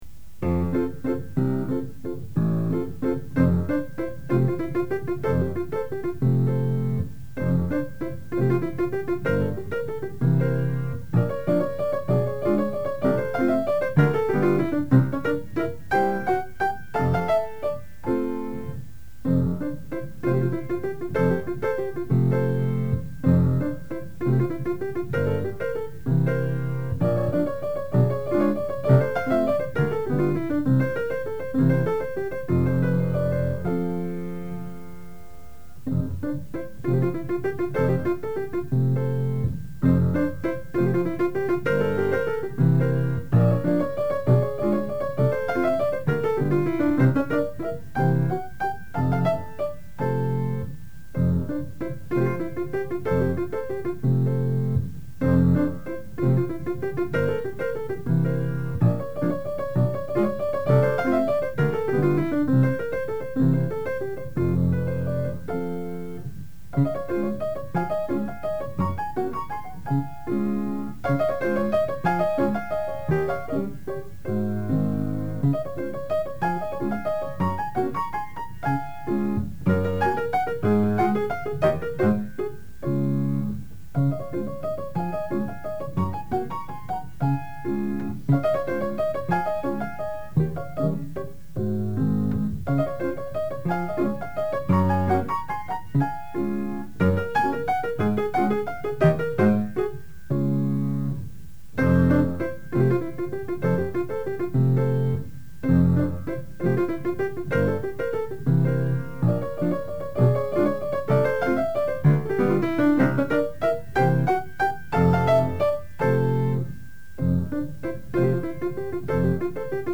First, it has ragtime rhythms in the first strain. Second the fact of ragtime being written in waltz rhythms is unusual in itself.